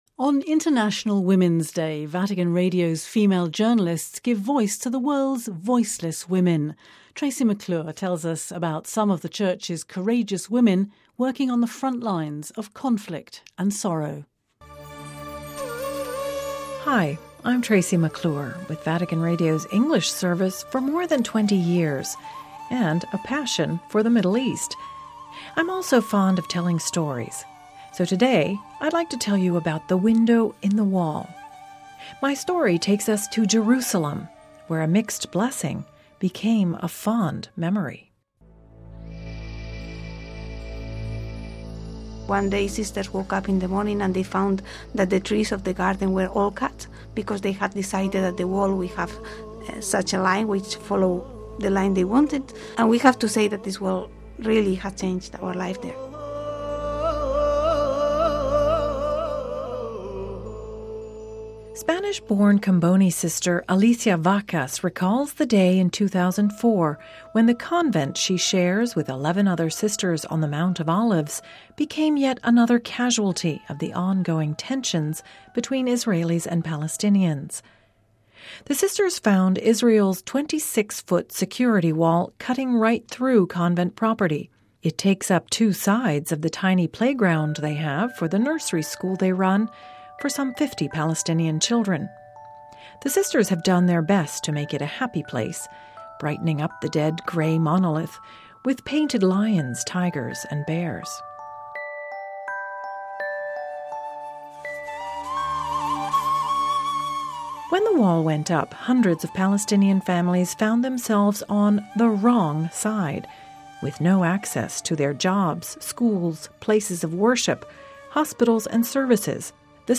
(Vatican Radio) On International Women’s Day, Vatican Radio’s female journalists give voice to the world’s voiceless women.